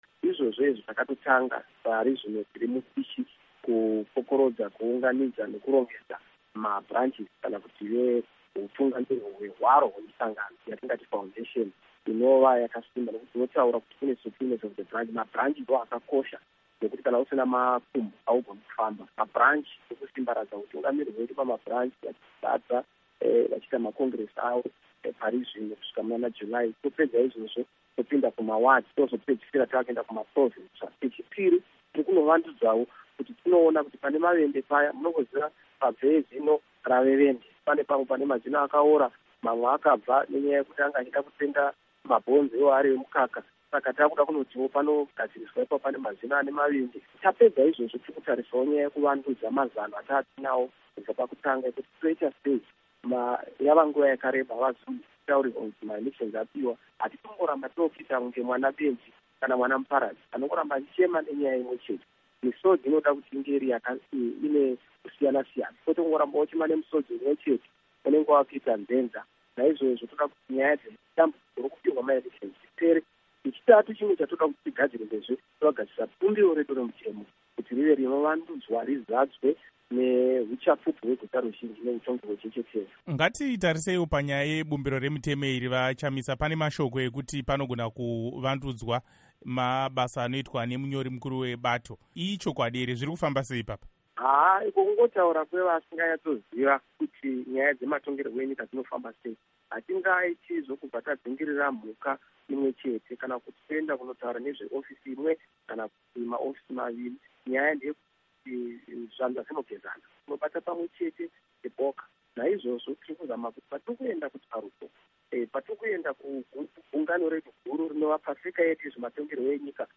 Hurukuro naVaNelson Chamisa